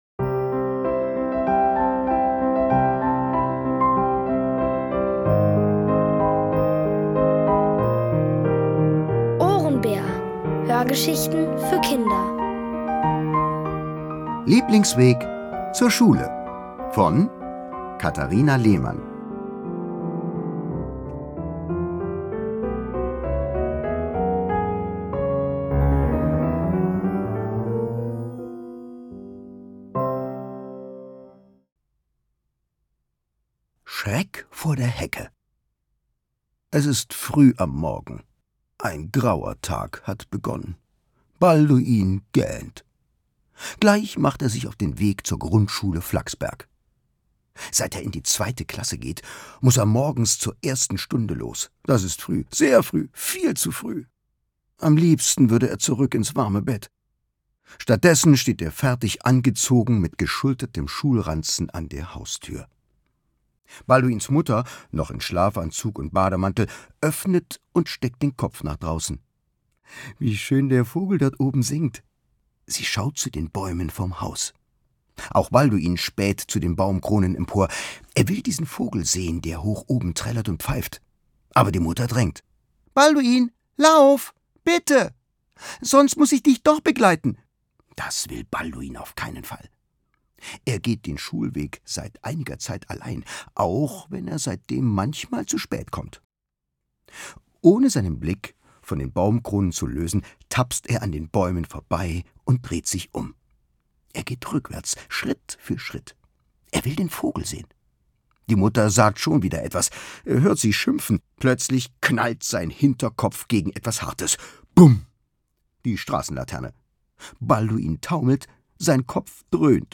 Von Autoren extra für die Reihe geschrieben und von bekannten Schauspielern gelesen.
Es liest: Thomas Nicolai.